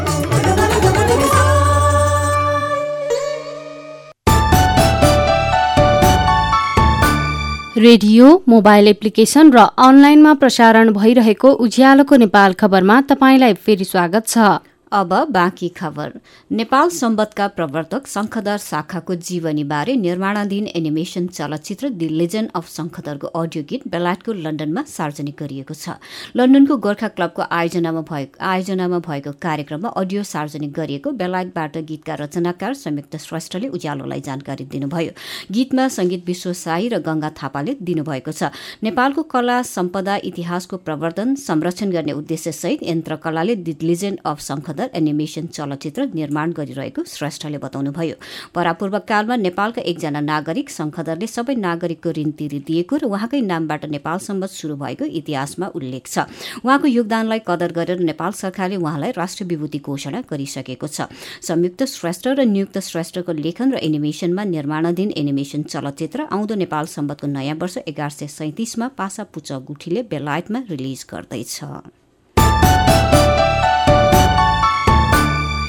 News in Ujjyalo FM